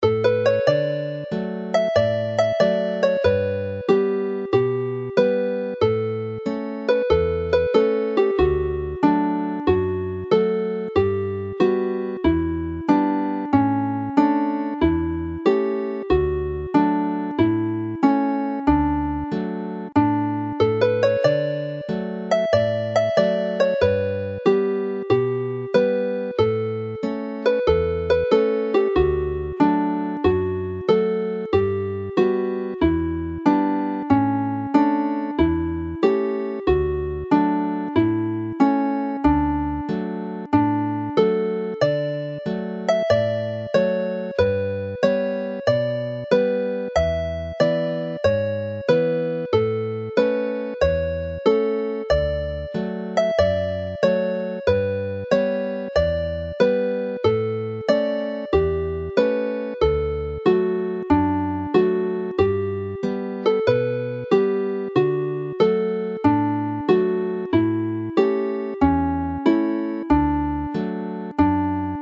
The sound files for the two tunes run as hornpipes and the score illustrations shown below the scores of the tunes give a fairly accurate portrayal of this.
Play the tune slowly